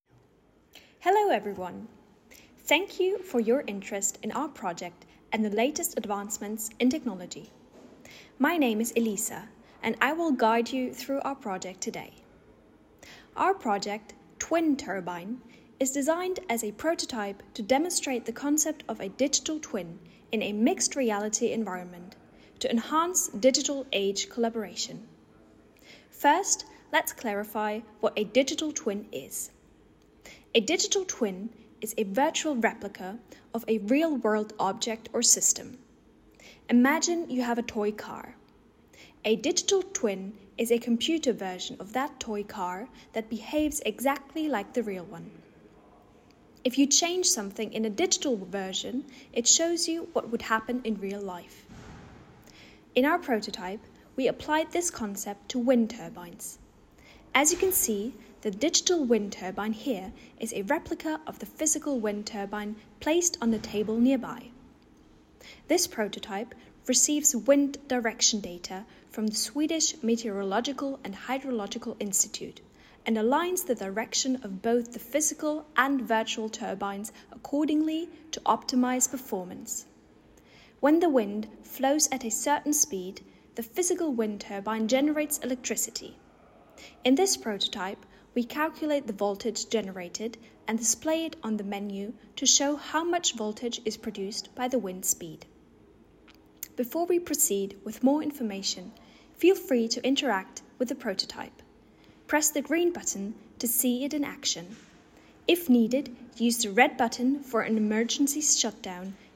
Audio Narration added